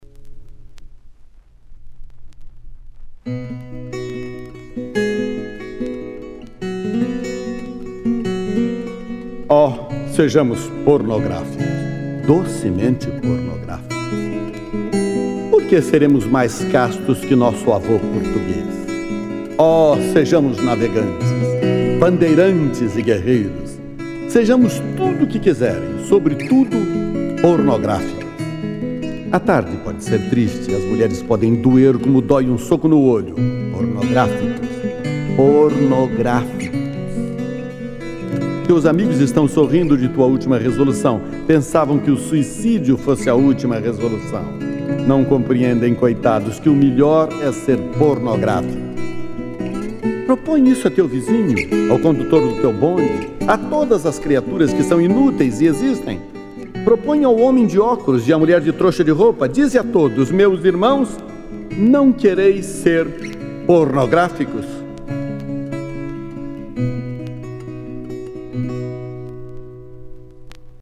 Carlos Drummond de Andrade interpretado por Lima Duarte - Músicas: Roberto Corrêa